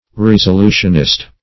Resolutionist \Res`o*lu"tion*ist\, n. One who makes a resolution.
resolutionist.mp3